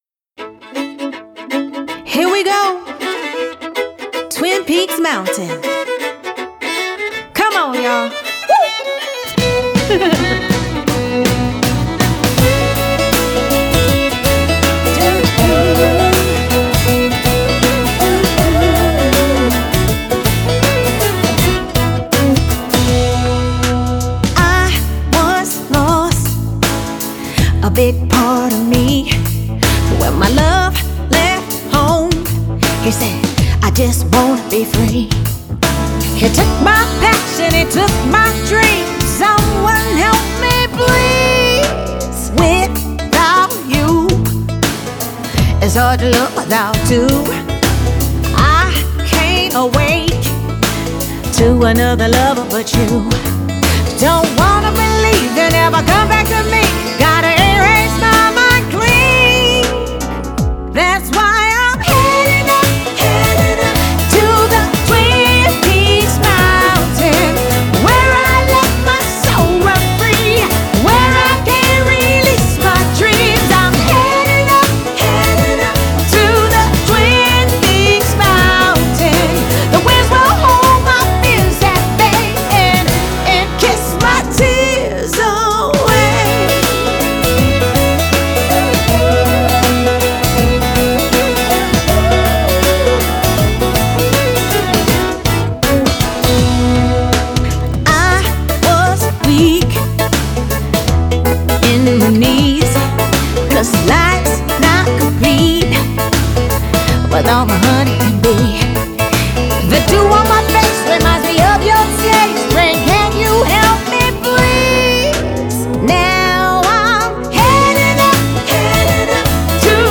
Steel Guitar
Fiddle
“Her voice is raw and powerful!